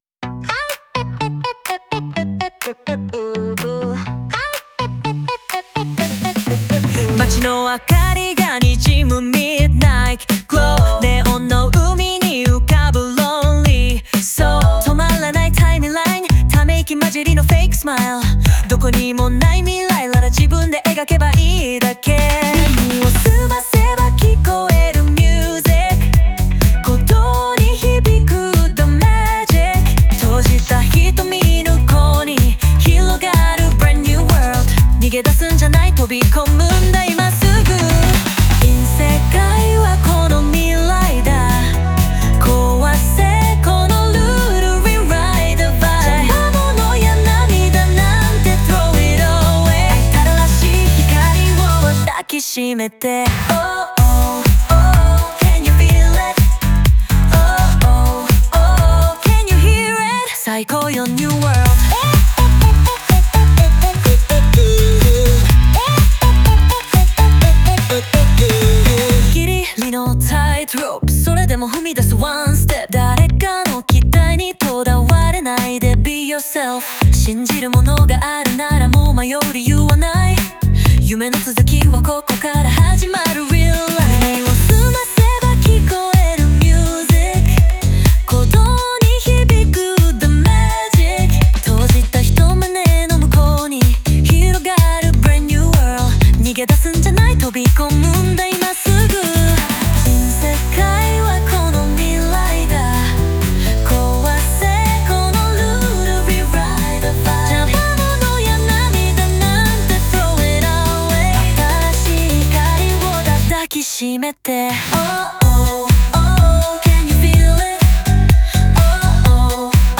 オリジナル曲♪
リズミカルなフレーズや英語を取り入れ、勢いと高揚感を演出し、聴く人を新しい世界へと誘うような楽曲になっている。